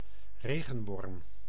Ääntäminen
IPA: /'reɣəʋɔrm/